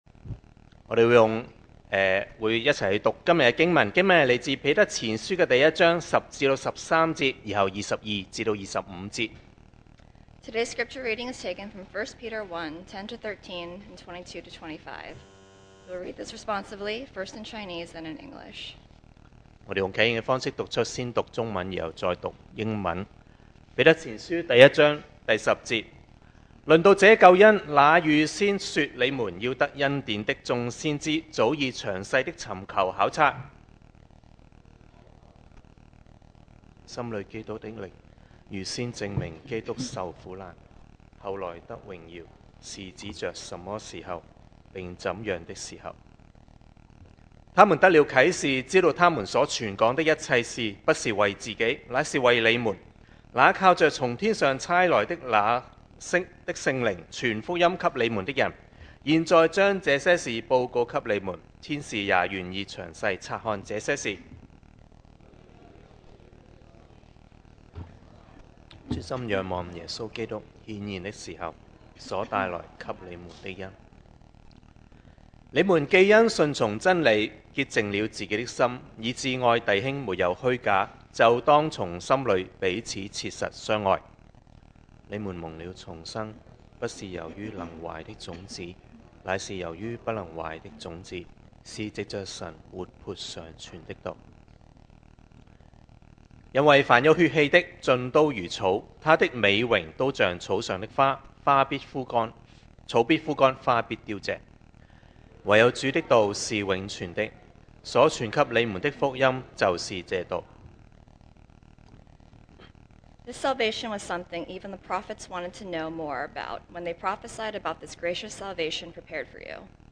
2023 sermon audios
Service Type: Sunday Morning